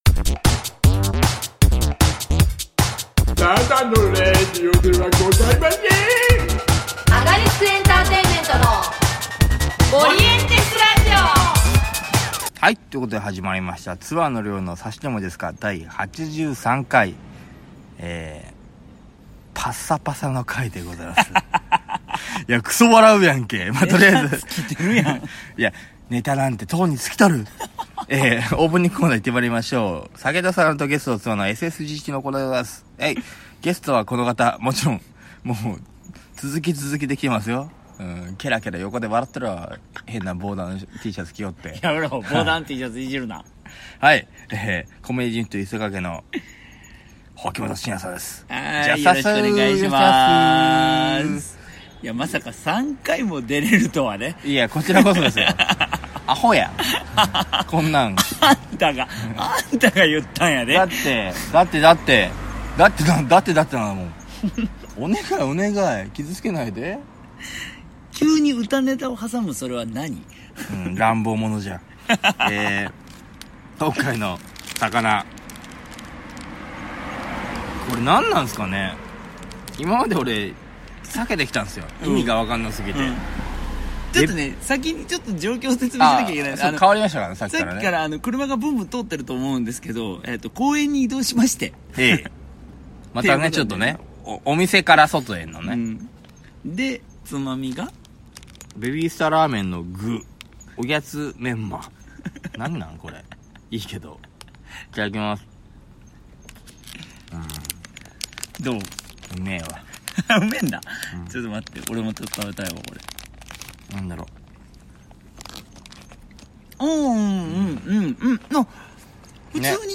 ●毎回ゲストを一人お招きし、むやみやたらに喋りあう対談風ダラダララジオです。